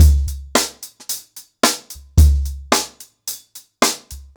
HarlemBrother-110BPM.7.wav